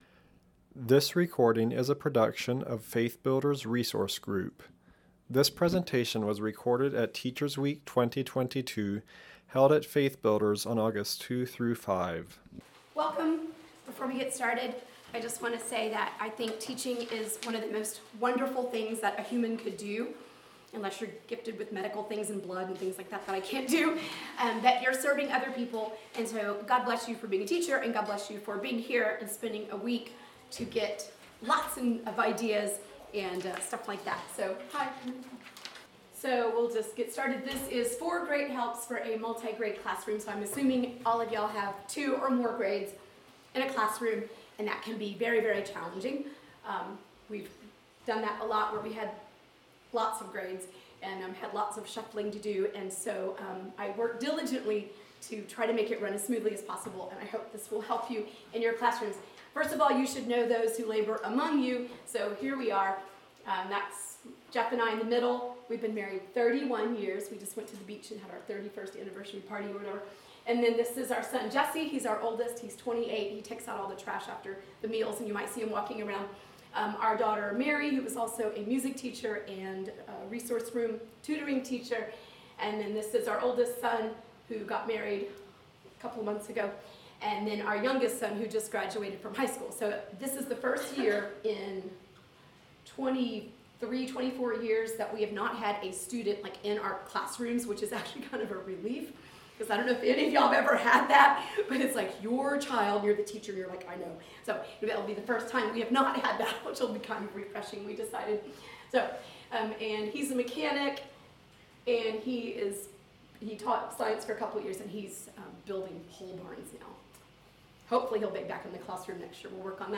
This session covers four ways you can work toward an efficient, organized classroom with motivated and happy students. This may be especially helpful for teachers of multi-grade classrooms, and is presented from the perspective of a teacher with 8 years of experience teaching in a multi-grade classroom.
Home » Lectures » Four Great Helps for a Multi-grade Classroom